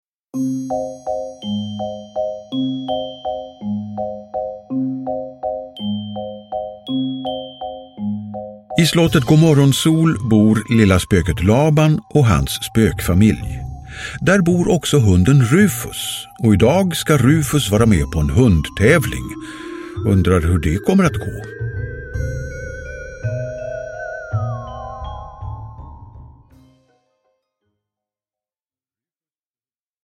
Lilla Spöket Laban: Rufus på hundtävling – Ljudbok – Laddas ner